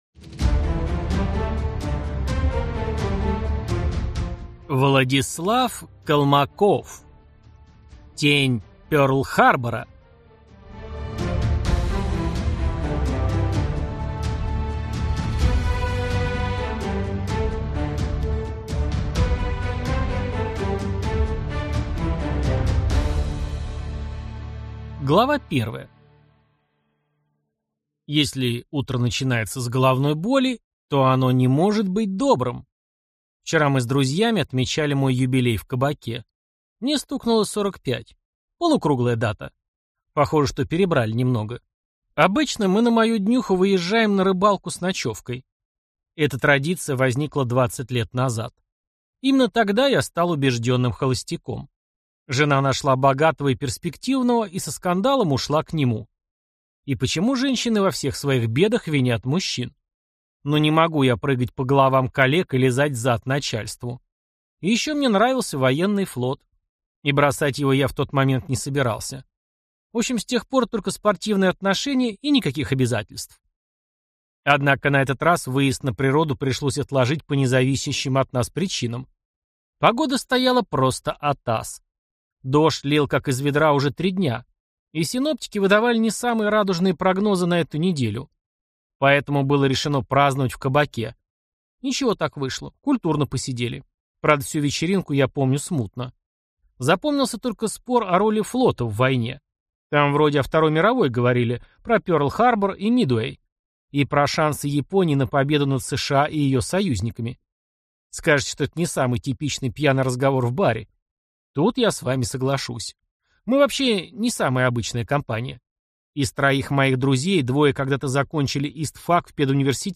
Аудиокнига Тень Перл-Харбора | Библиотека аудиокниг